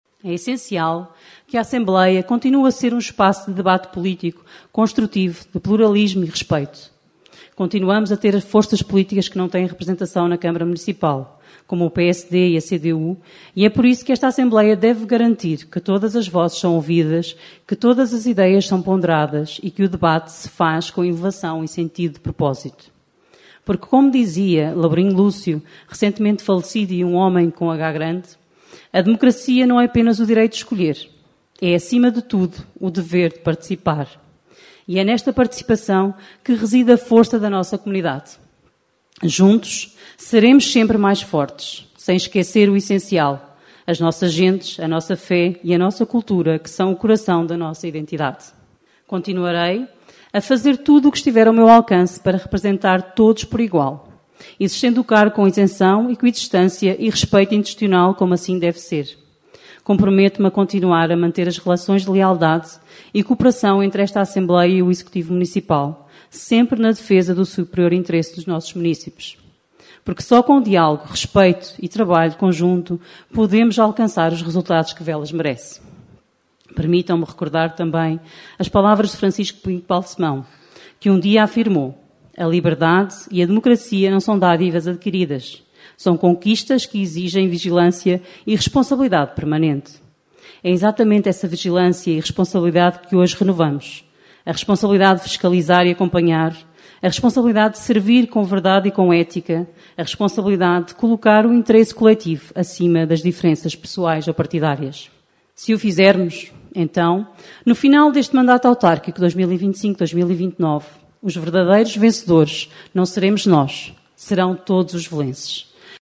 Falando na cerimónia que decorreu no Auditório Municipal de Velas, a presidente da Assembleia Municipal, Lena Amaral, que inicia o seu segundo mandato à frente deste órgão, destacou o facto de ser um Assembleia um espaço de debate político com pluralismo e respeito, garantindo voz a todos os intervenientes.
LenaAmaral_TomadaPosseAMV_4nov25.mp3